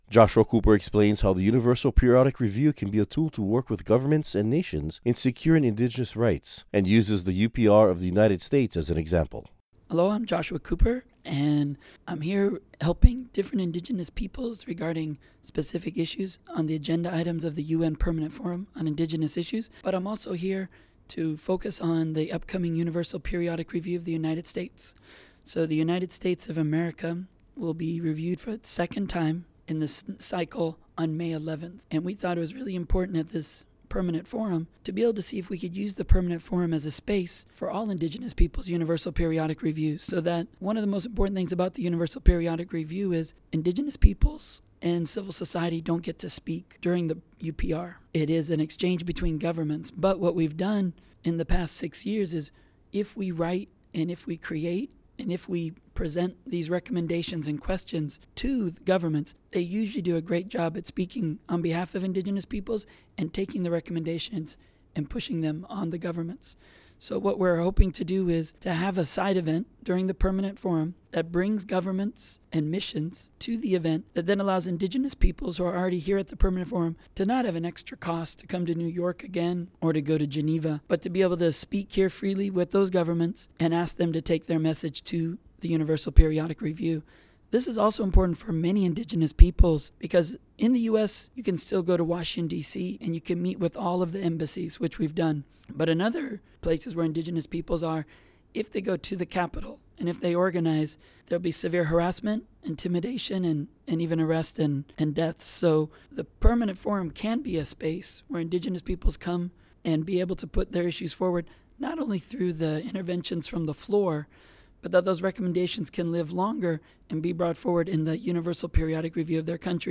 Recording Location: UNPFII 2015
Type: Interview